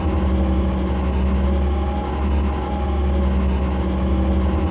LiftMoving.wav